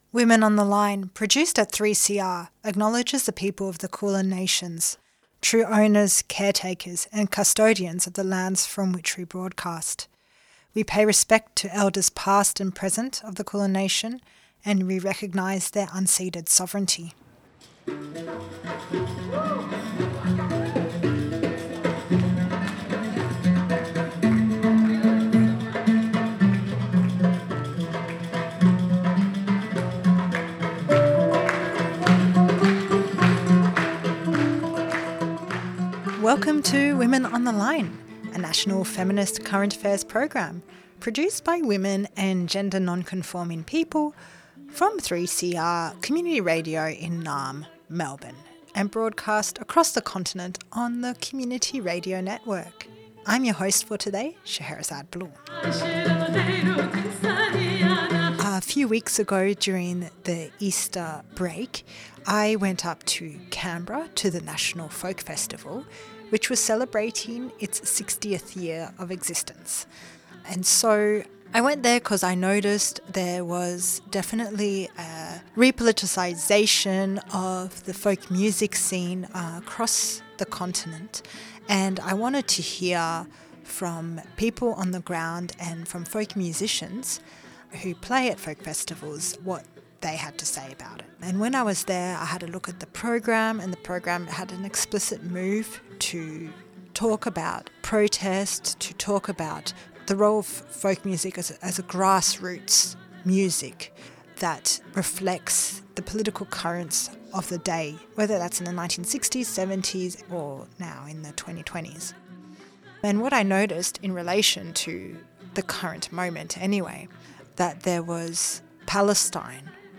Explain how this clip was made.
This week, we take you to the National Folk Festival in Canberra, where we chat with three women from Dabke & Tatreez, a music and dance ensemble. Dabke & Tatreez formed in Sydney (Gadigal land) with musicians and dancers from, and with roots, in Palestine, Lebanon, Turkiye, Cyprus and Indonesia.